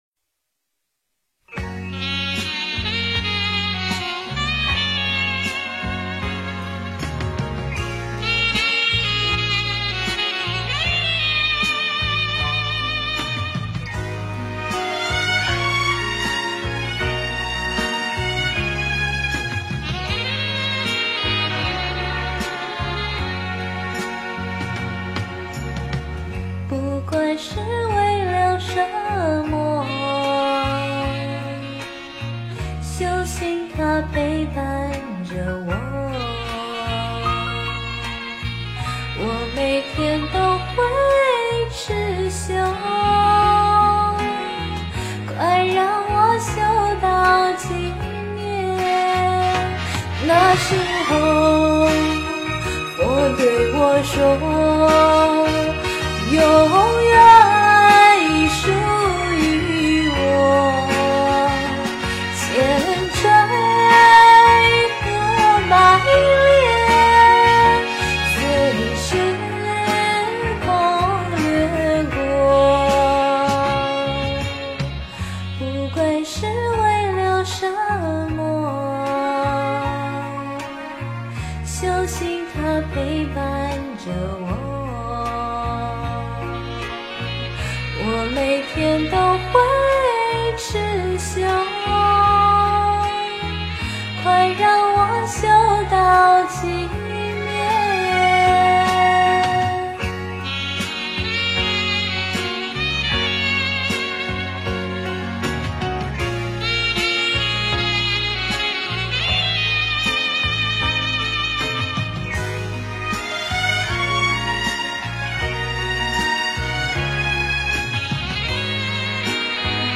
千锤百炼 诵经 千锤百炼--佛教音乐 点我： 标签: 佛音 诵经 佛教音乐 返回列表 上一篇： 轮回 下一篇： 生死不离 相关文章 菩萨般若波罗蜜--佚名 菩萨般若波罗蜜--佚名...